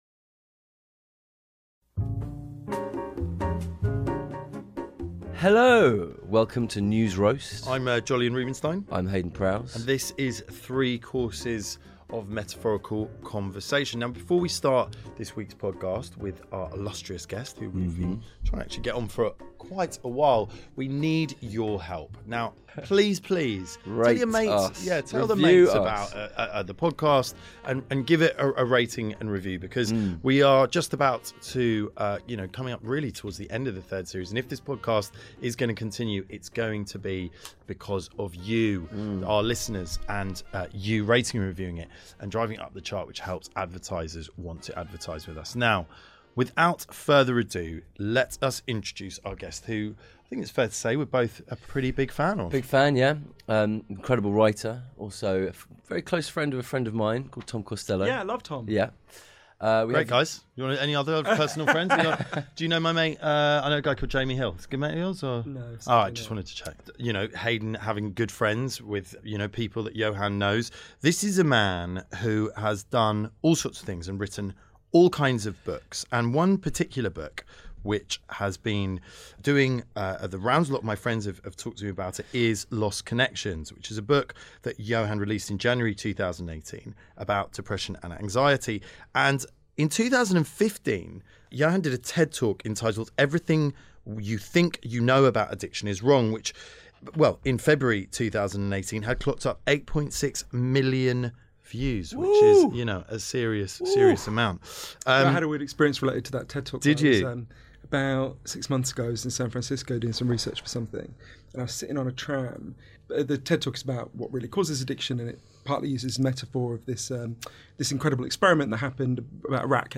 Writer and journalist, Johann Hari, steps into the studio to talk about some of the themes addressed in his new book, Lost Connections.